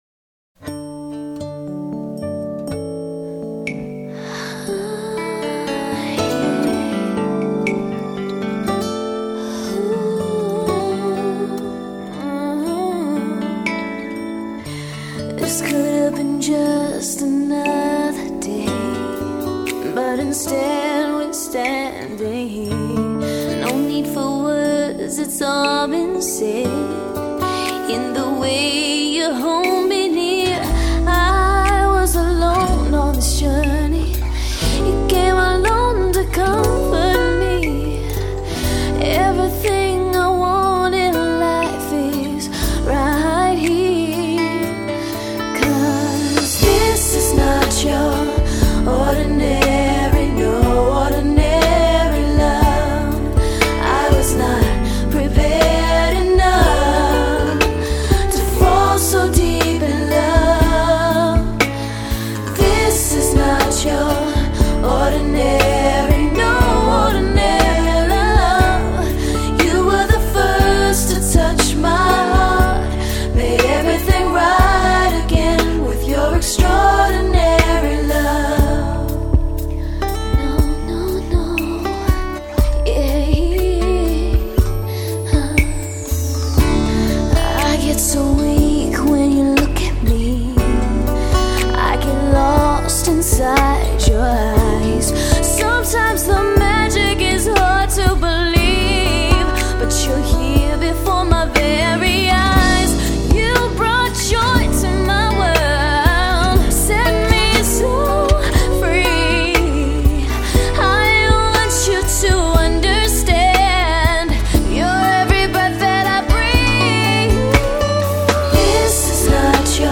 Genre: Pop-Rock.